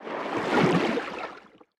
Sfx_creature_seamonkey_swim_slow_06.ogg